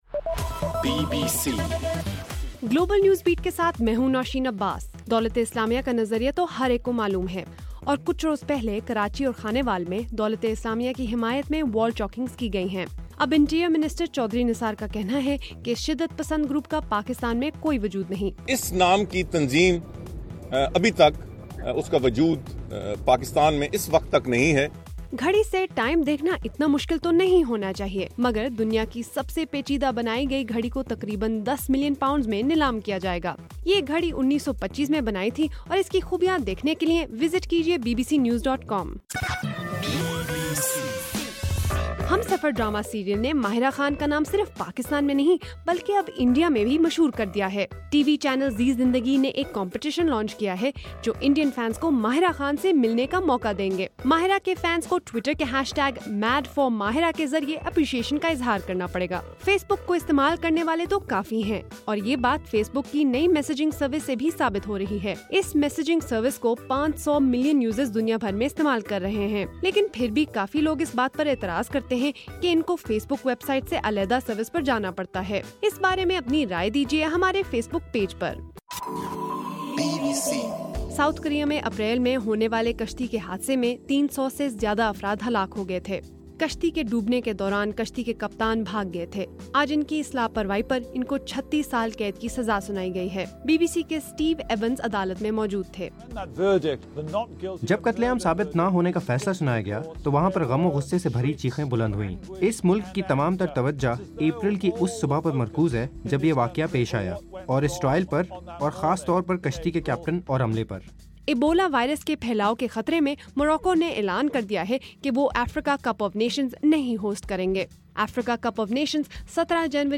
نومبر 11: رات 9 بجے کا گلوبل نیوز بیٹ بُلیٹن